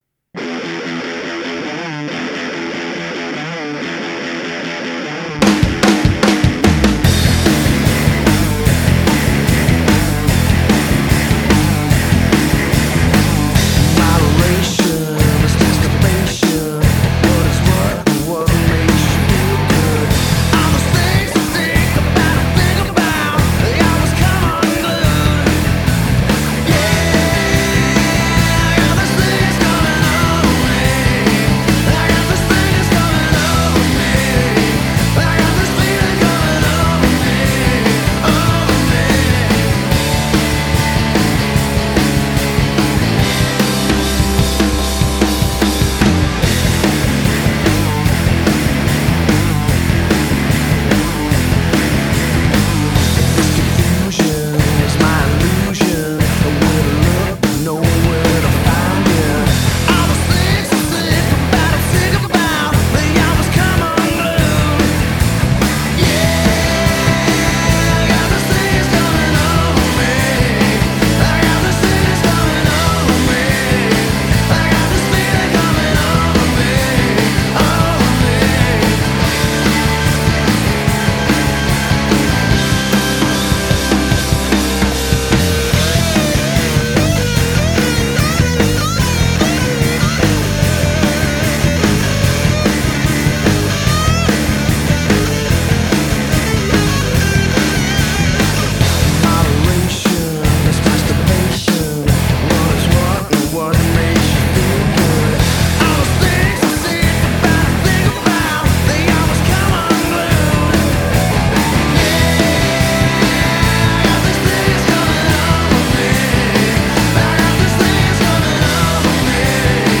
融入爵士、藍調、放克等不同風格